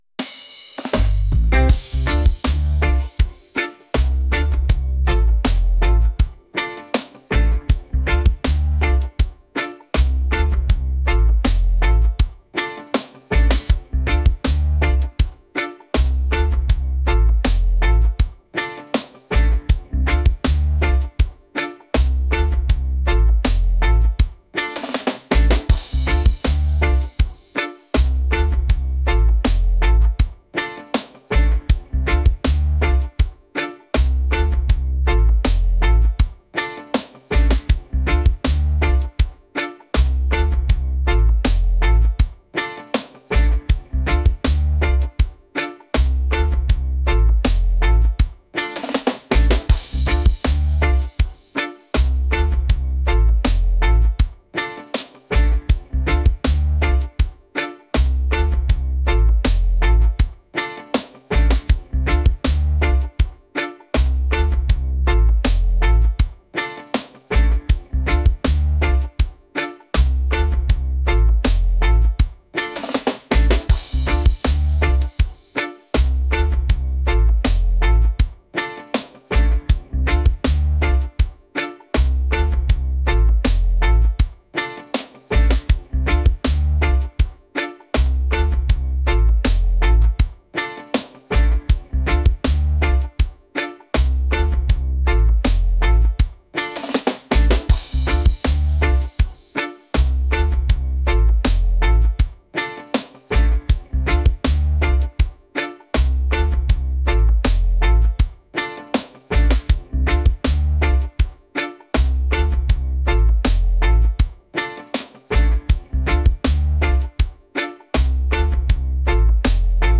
dreamy | reggae | upbeat